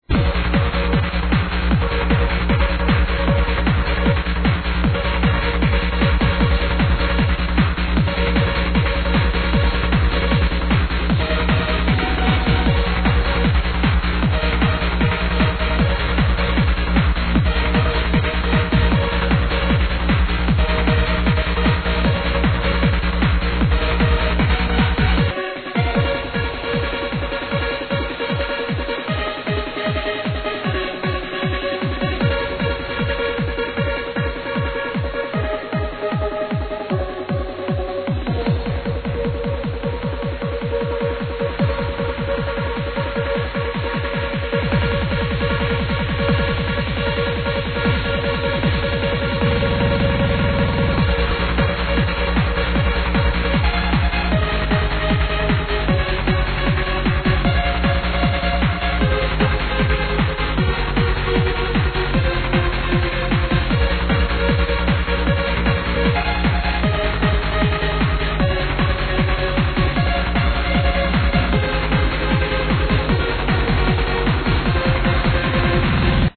Never heard it pitched up that hard either.